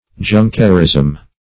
\Jun"ker*ism\